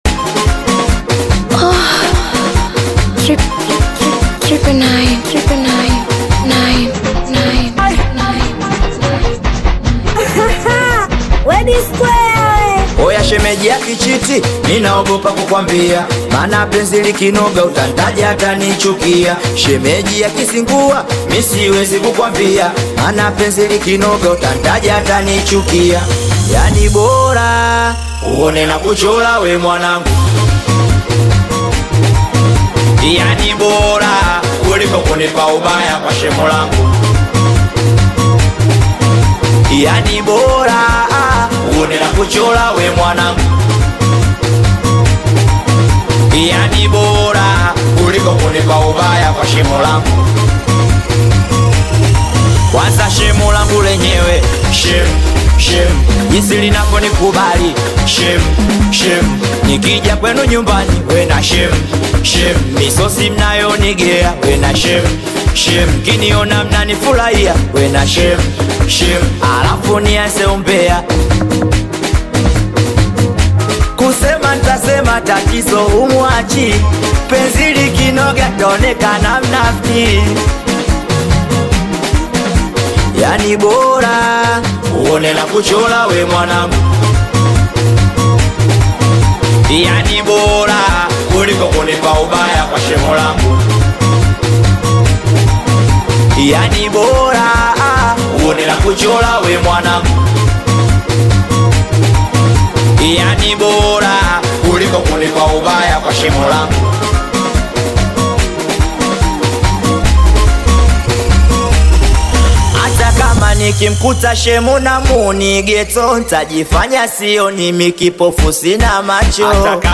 Singeli music track
Tanzanian Bongo Flava singeli
Singeli song